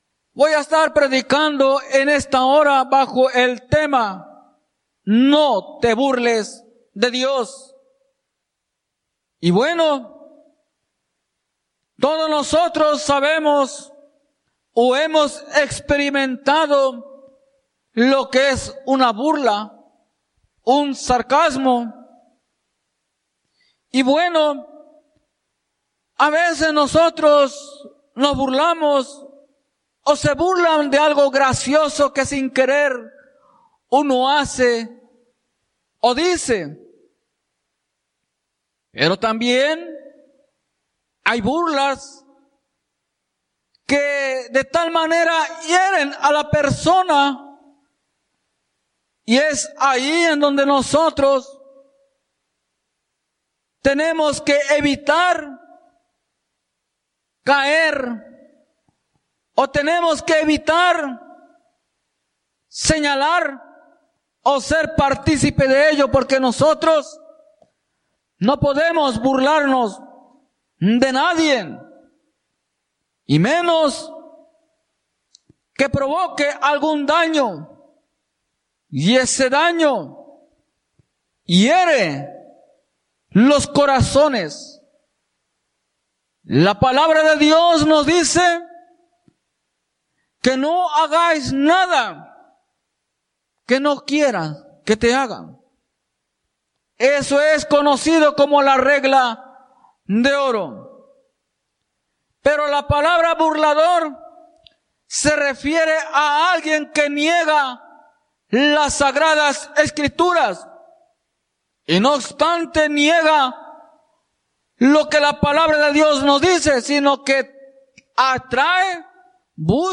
Norristown,PA